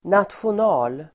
Uttal: [²natsjon'a:l-]